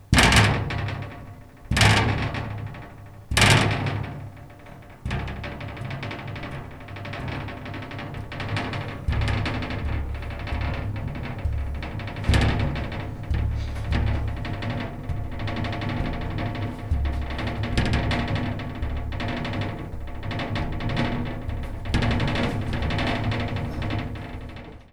• glass hitt shaking from a display case.wav
Old china display case being gently hit with two fingers.
glass_hitt_shaking_from_a_display_case_z8Y.wav